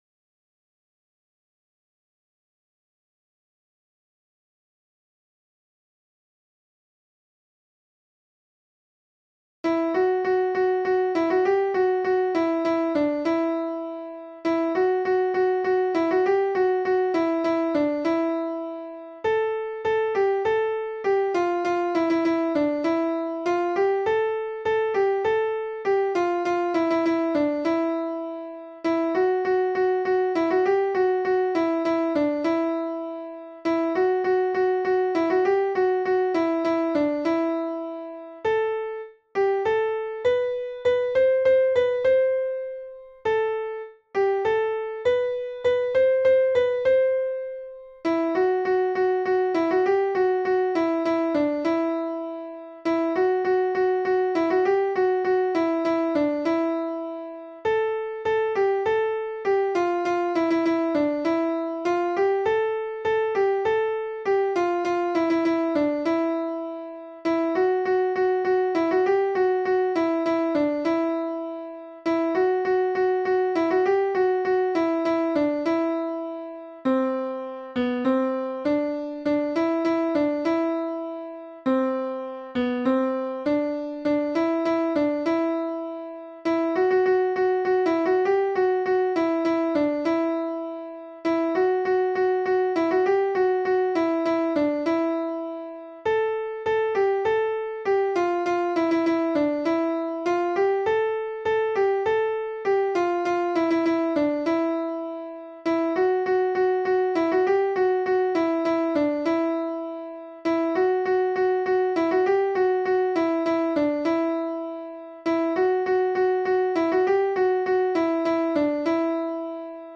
Mezzo Soprano (version piano